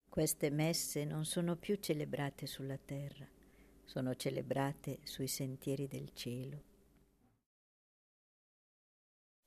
Preghiera mp3 S. Messa canto mp3